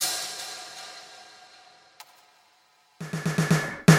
描述：两个小节的计数填充，速度为120 bpm。用Cubase 9制作
Tag: 120 bpm Weird Loops Drum Loops 690.77 KB wav Key : Unknown